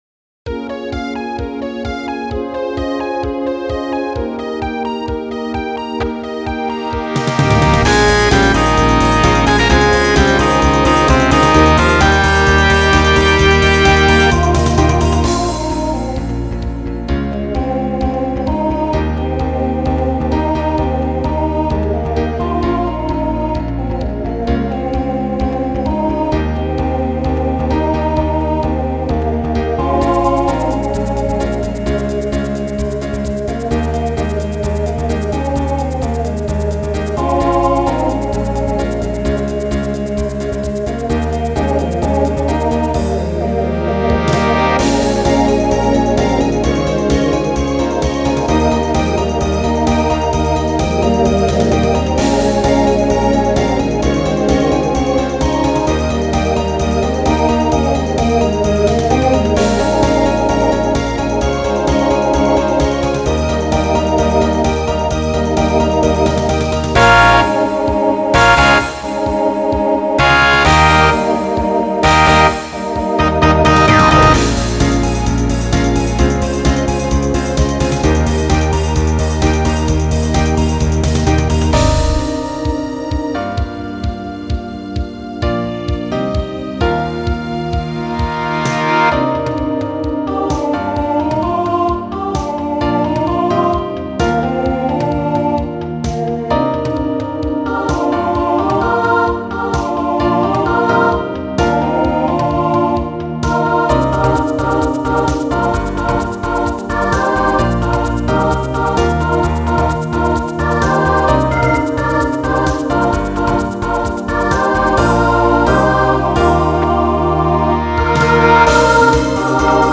TTB/SSA